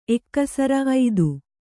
♪ ekkasara